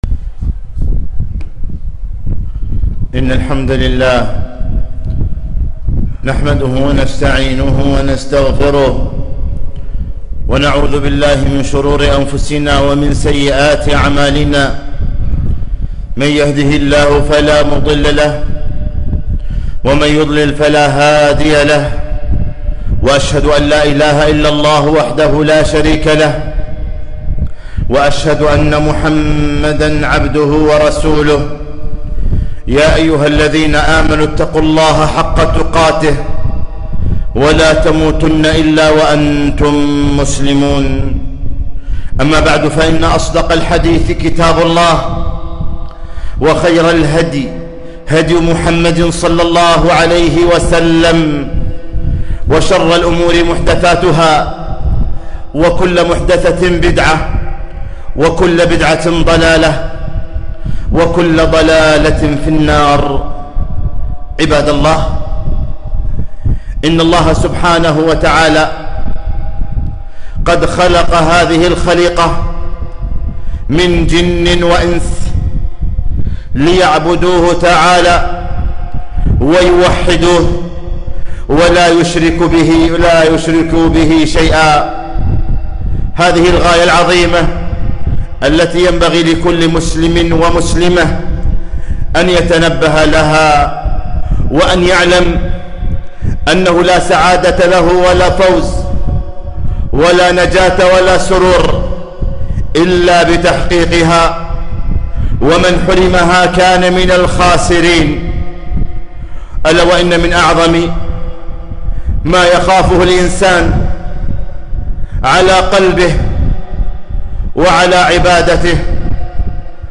خطبة - لا تتعب نفسك إذا كنت مرائيا - دروس الكويت